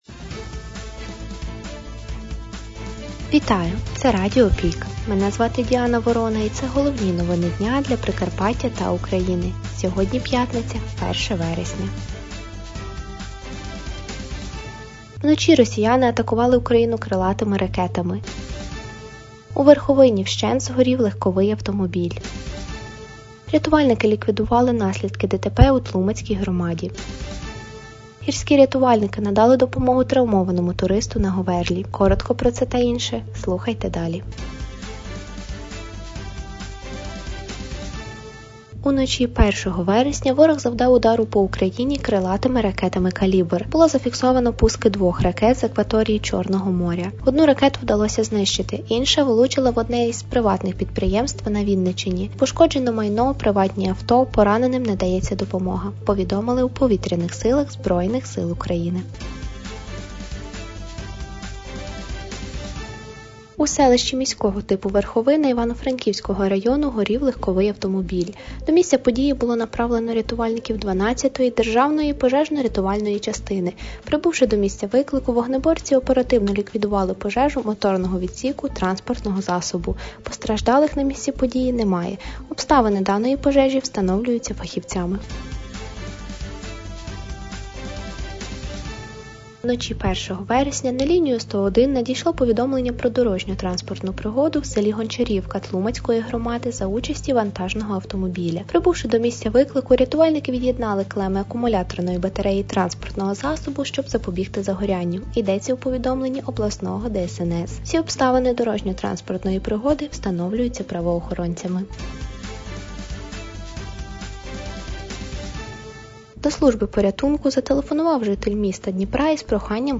Пропонуємо вам актуальне за останній день літа - у радіоформаті.